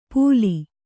♪ pūli